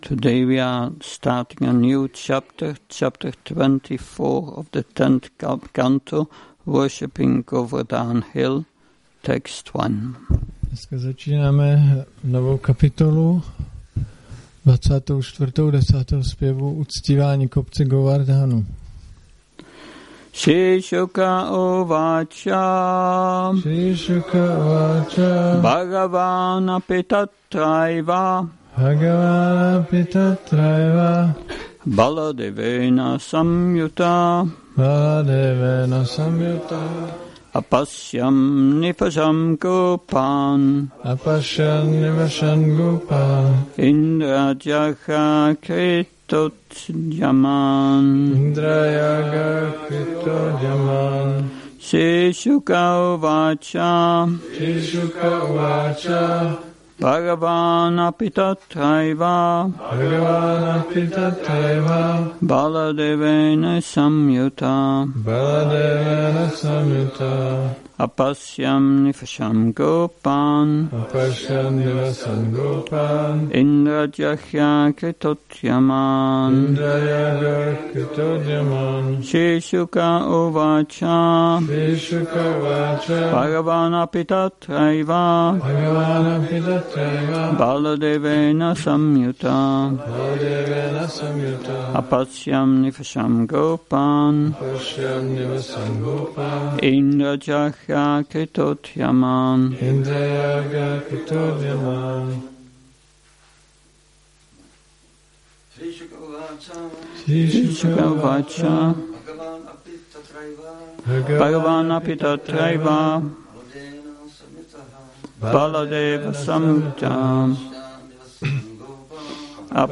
Přednáška SB-10.24.1 – Šrí Šrí Nitái Navadvípačandra mandir